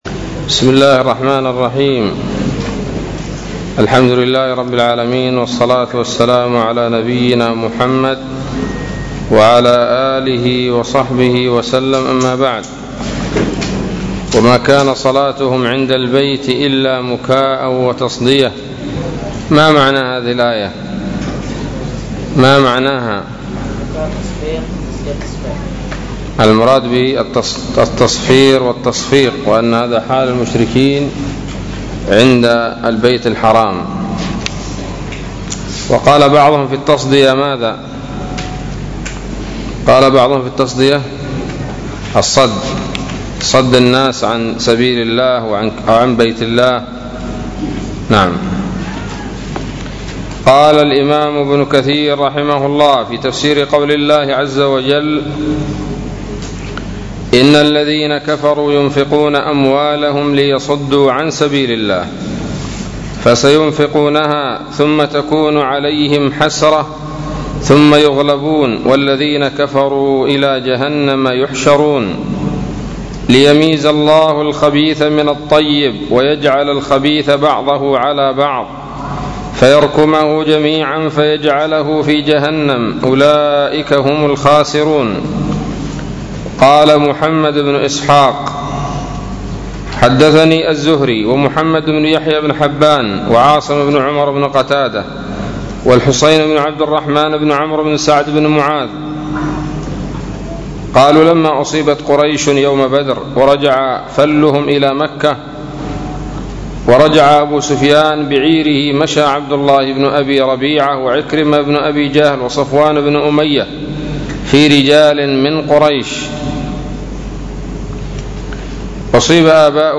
008 سورة الأنفال الدروس العلمية تفسير ابن كثير دروس التفسير